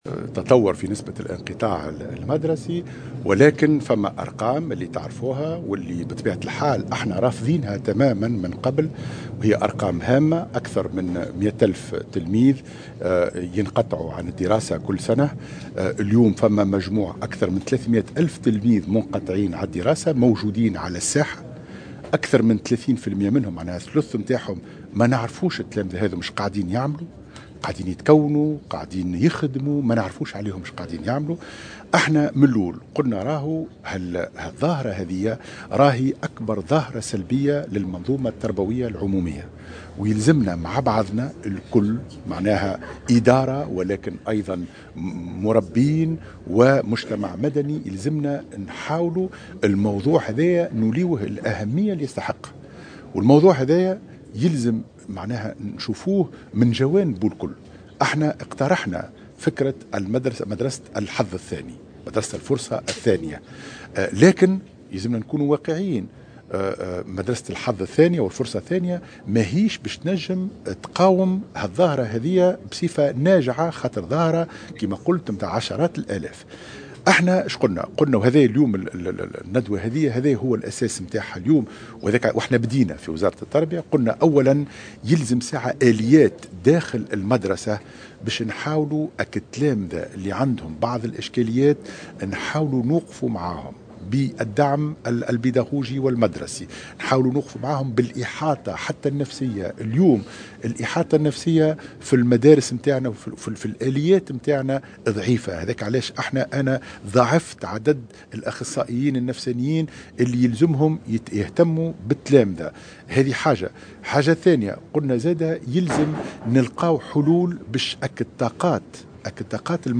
وأكد في تصريح لمراسلة "الجوهرة اف أم" على هامش ملتقى في قمرت، أهمّية توفّر الاختصاص عند انتداب المربّين وانفتاحهم على مهارات أخرى في علاقة بتنمية قدرات التلاميذ واقبالهم على الدراسة وتفادي الانقطاع المدرسي المبكر.